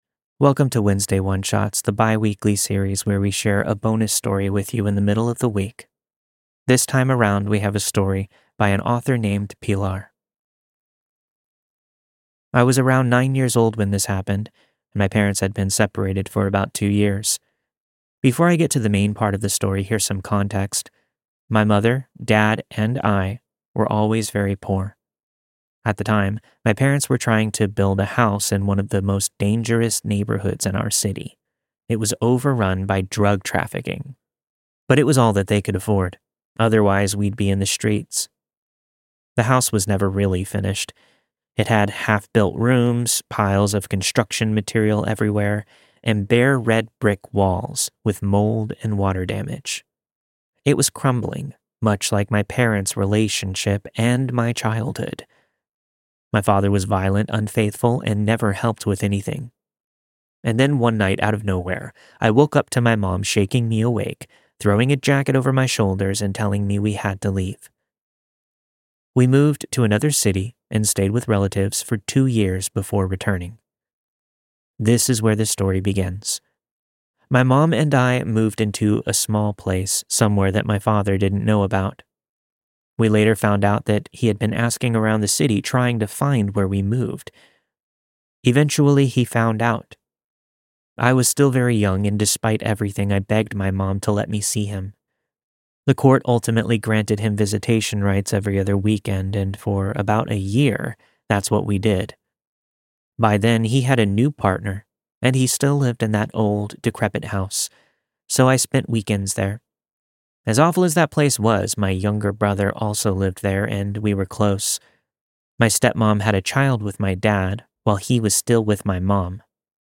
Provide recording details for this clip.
The story you've heard this week was narrated and produced with the permission of the respective author.